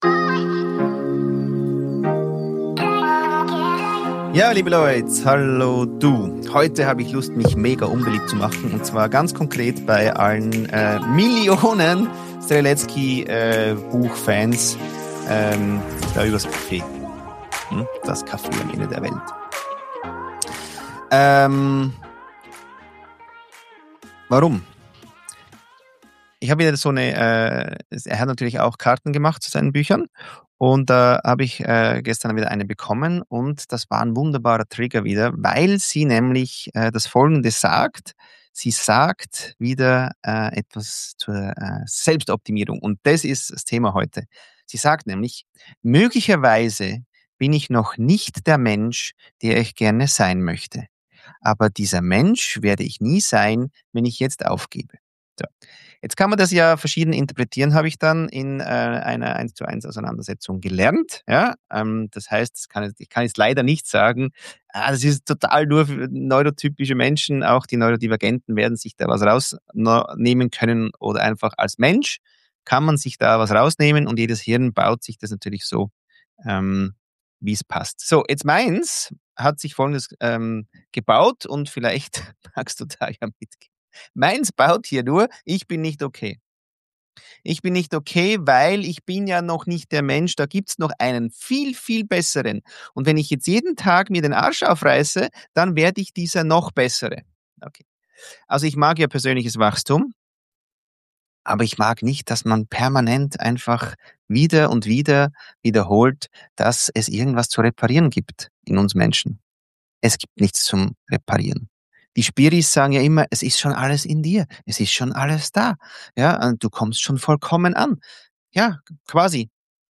Solo-Episode